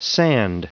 Prononciation du mot sand en anglais (fichier audio)
Prononciation du mot : sand